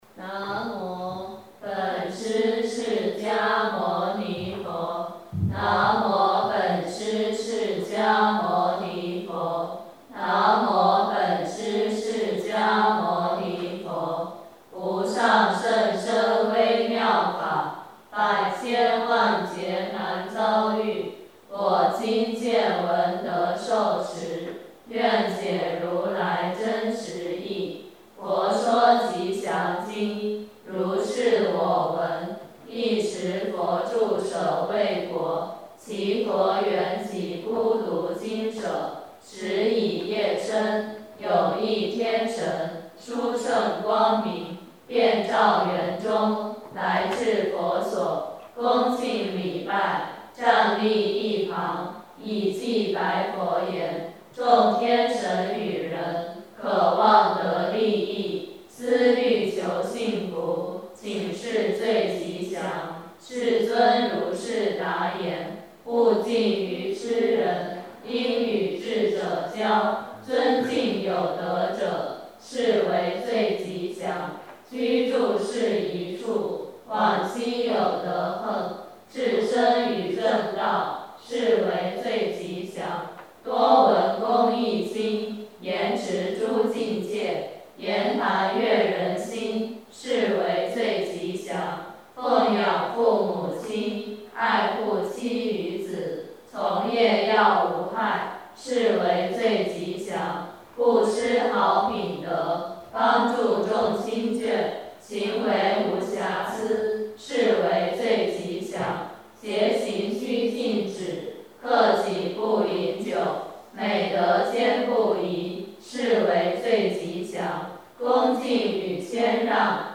晚殿--普寿寺梵呗专辑.mp3